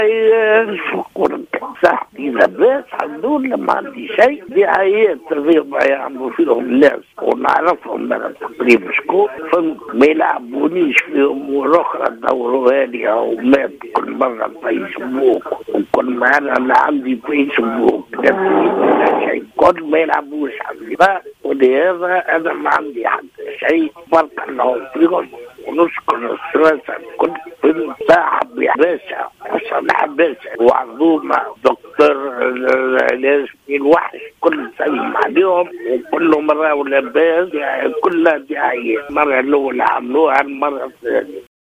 في اتصال هاتفي: احمد المغيربي ينفي شائعات وفاته